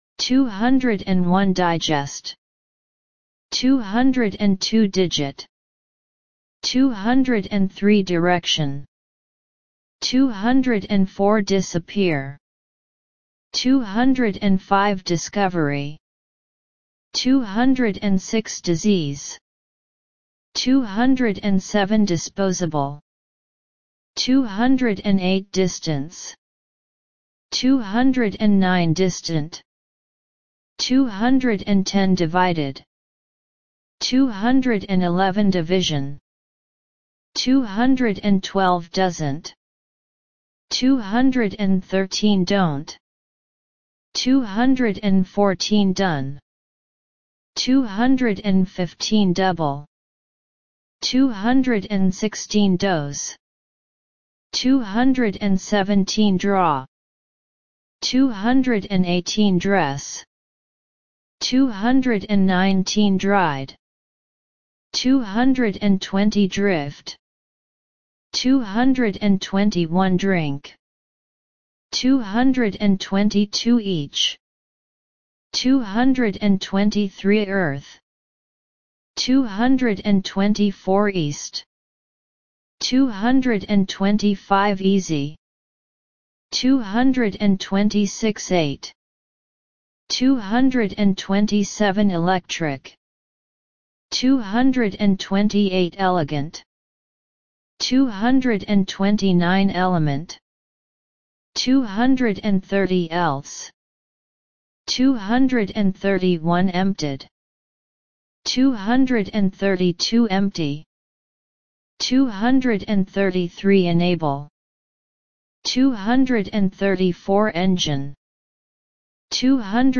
201 – 250 Listen and Repeat